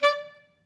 Clarinet
DCClar_stac_D4_v3_rr2_sum.wav